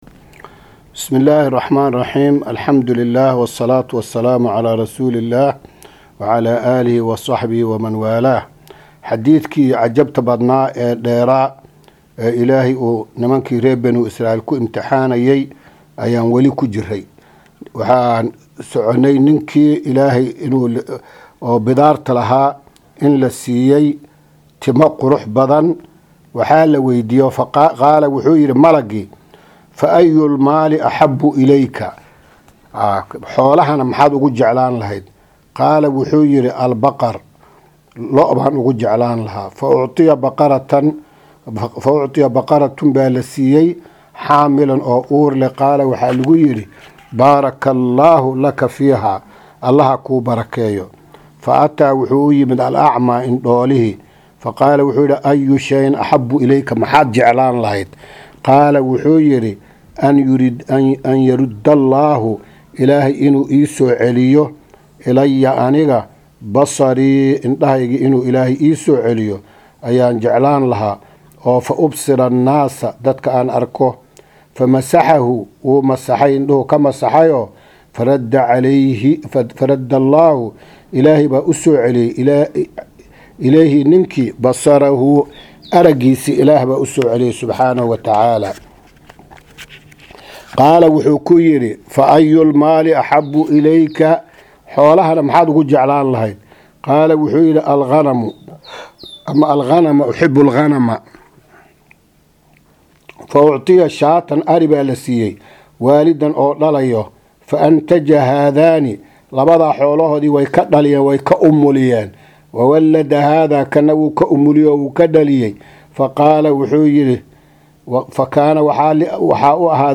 Maqal- Riyaadu Saalixiin – Casharka 9aad